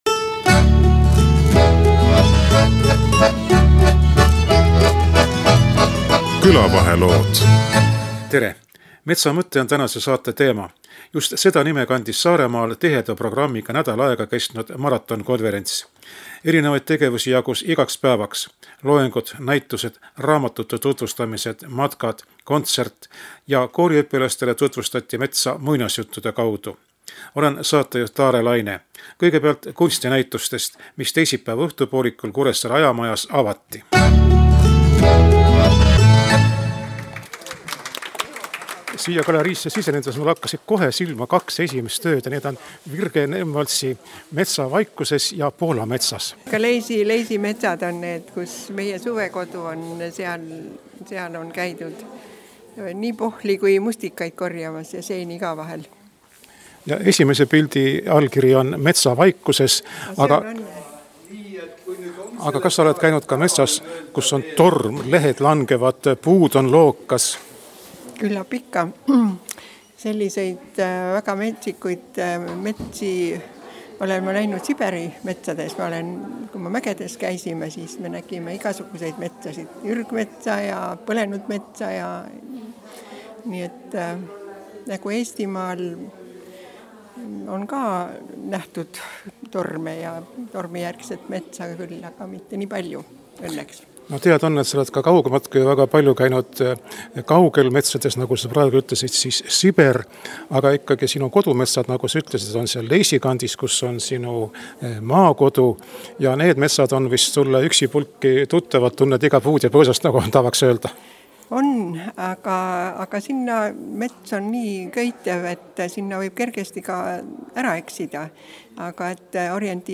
küsitleb nii osalejaid kui konverentsi korraldajaid.